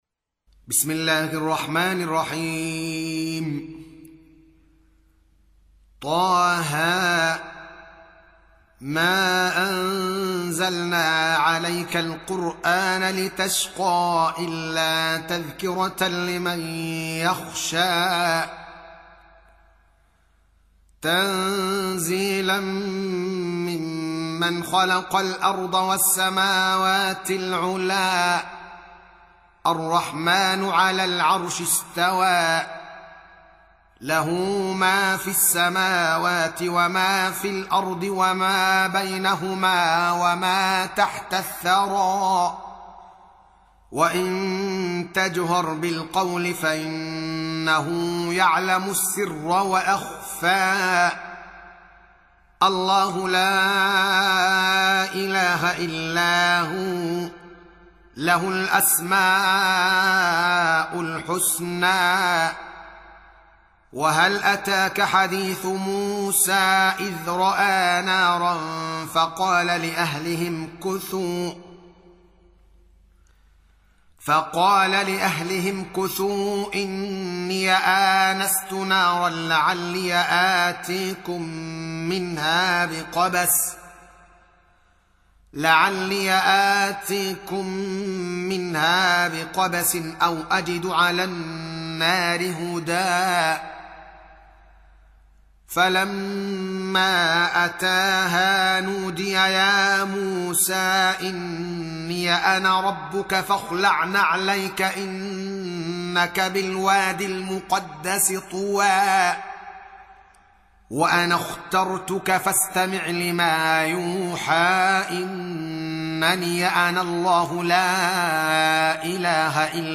Surah Repeating تكرار السورة Download Surah حمّل السورة Reciting Murattalah Audio for 20. Surah T�H�. سورة طه N.B *Surah Includes Al-Basmalah Reciters Sequents تتابع التلاوات Reciters Repeats تكرار التلاوات